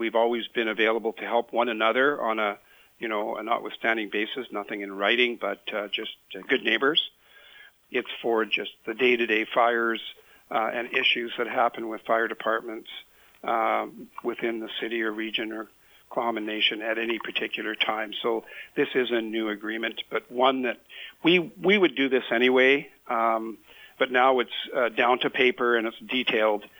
City Mayor Dave Formosa says this is for the day-to-day fires and issues that affect the local fire departments within the city, region or Tla’amin First Nation.